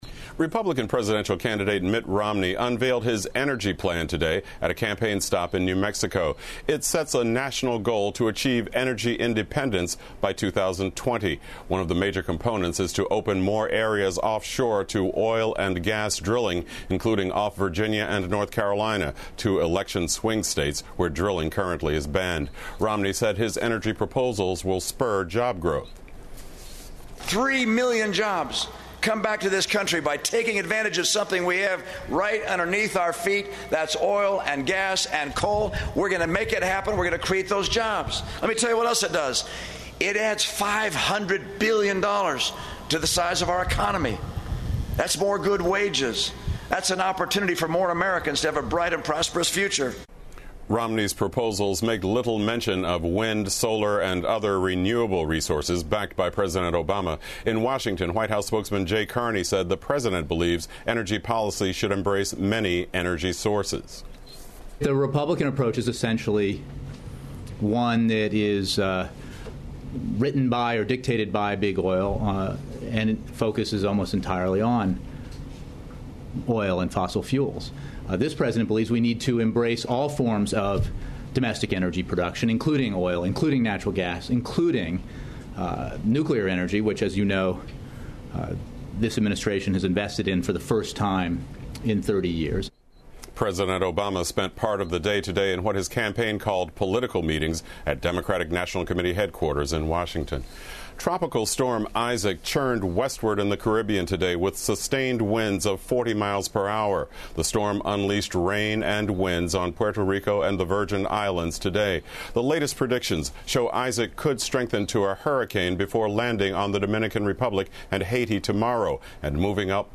PBS高端访谈:罗姆尼将能源独立作为优先政策 听力文件下载—在线英语听力室